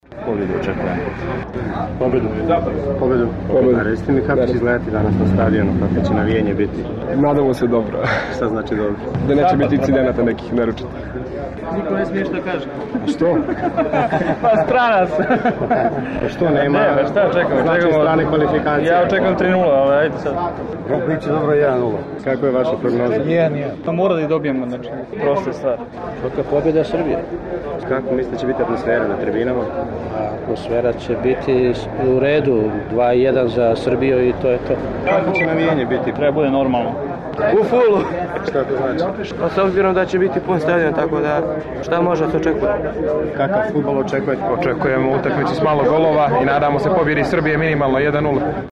Pred meč Srbija – Hrvatska kod stadiona Partizana, nedaleko od Marakane, u petak je bila gužva pred blagajnom:
Navijači pred utakmicu